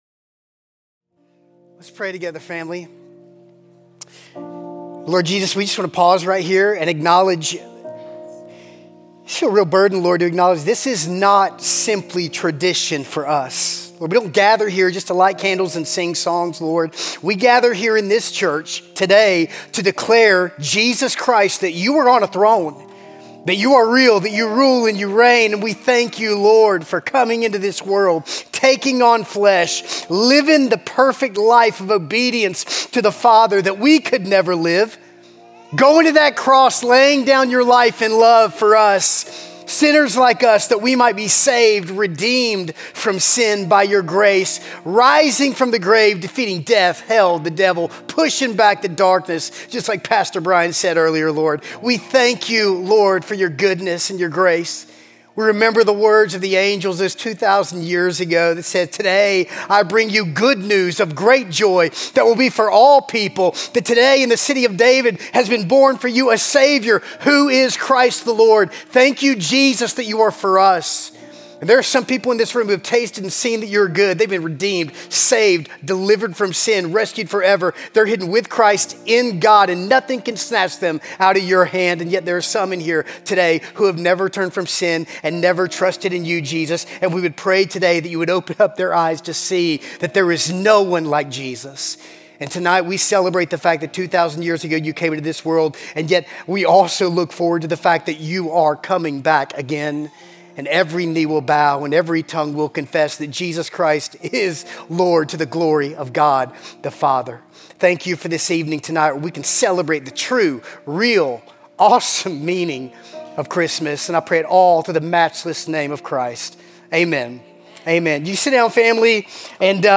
Christmas Eve Candlelight Service